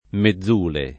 mezzule
[ me zz2 le ]